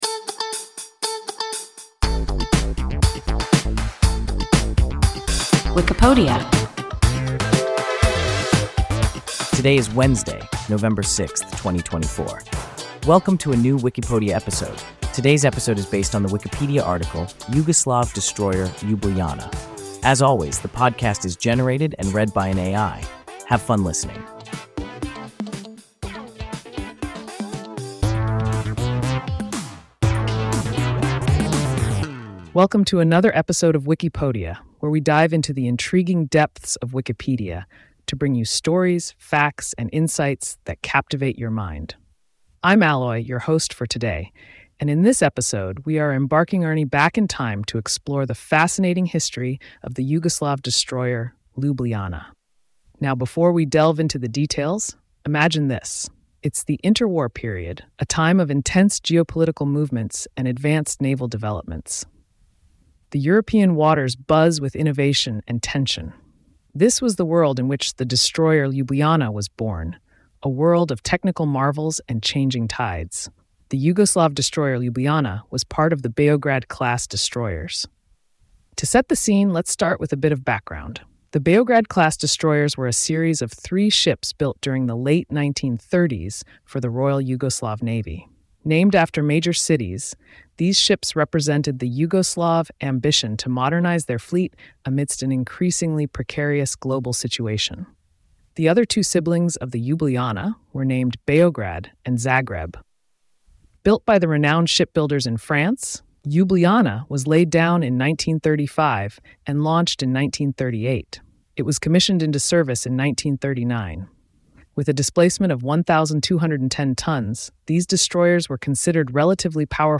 Yugoslav destroyer Ljubljana – WIKIPODIA – ein KI Podcast